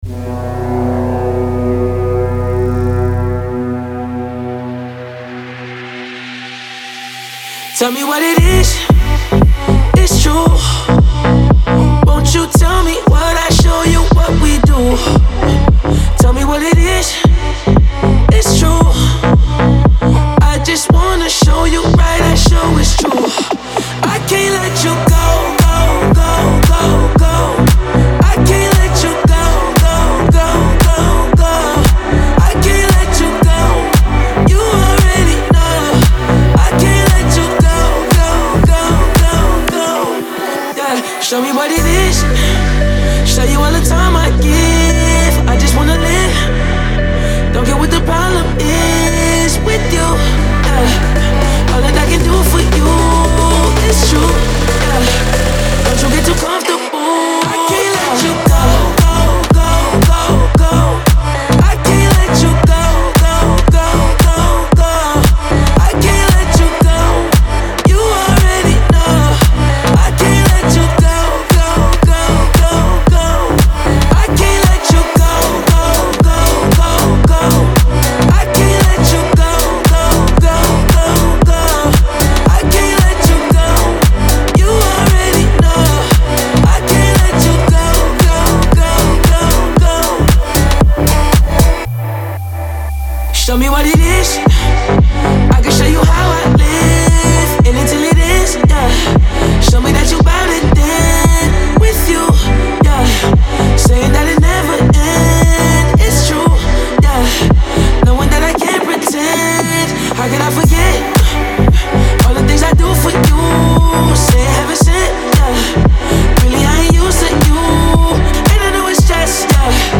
это энергичная трек в жанре хип-хоп и поп